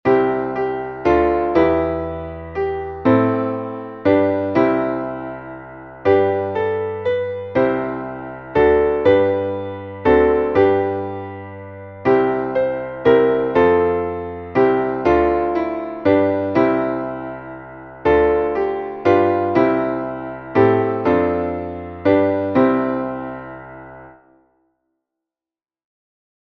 Traditionelles Neujahrs-/ Kirchenlied (19.